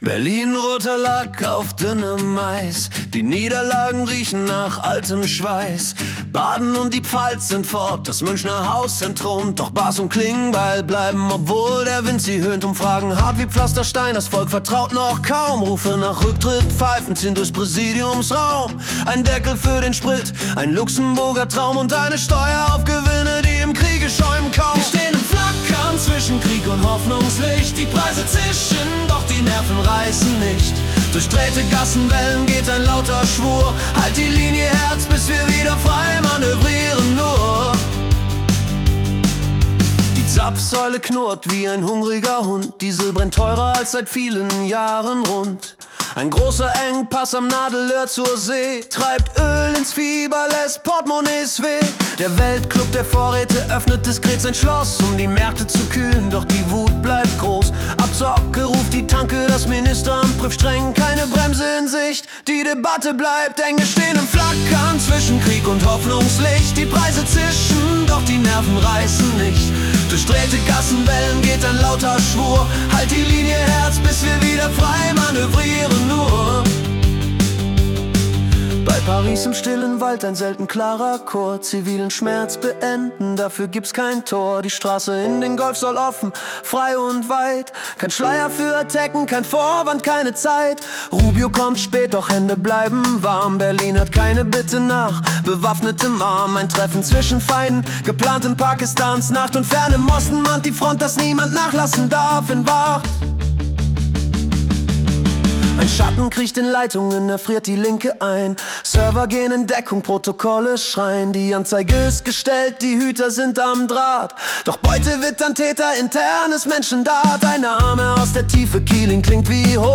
März 2026 als Rock-Song interpretiert.